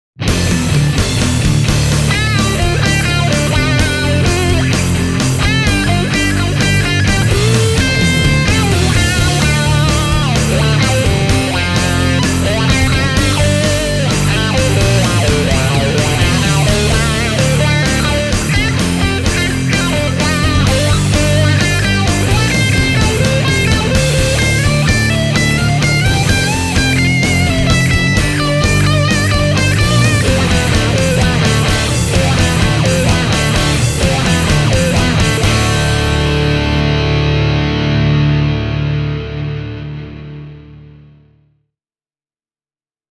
Duncanin Blackouts-humbuckerit tarjoavat runsaasti lähtötehoa ja selkeyttä, eikä ne ulise runsaalla gainella, mikä tekee niistä hyvän valinnan nyky-Metallille.